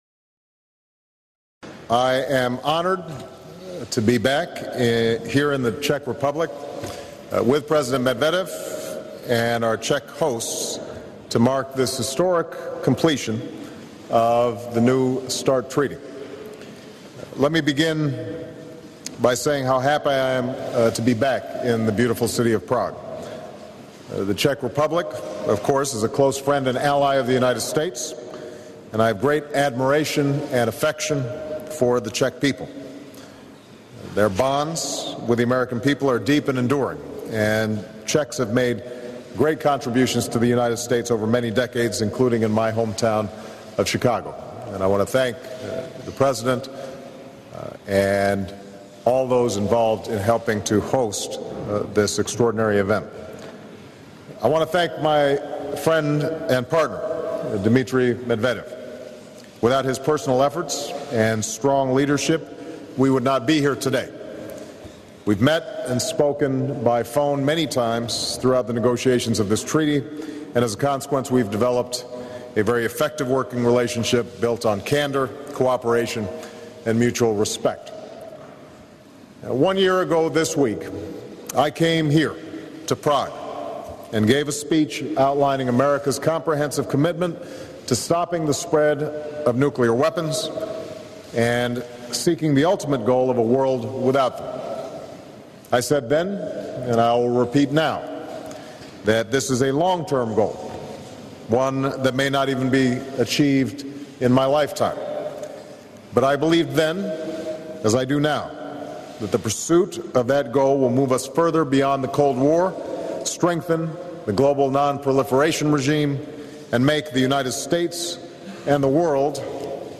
U.S. President Barack Obama and Russian President Dmitry Medvedev talk about the Strategic Arms Reduction Treaty after its signing
The presidents also answer questions from reporters.
Broadcast on C-SPAN, Apr. 8, 2010.